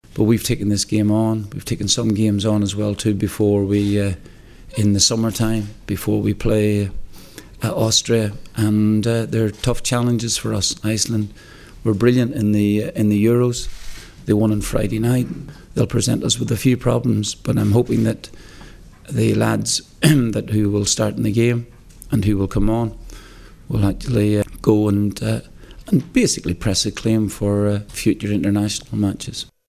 Here’s the manager on what he’s looking for from the game…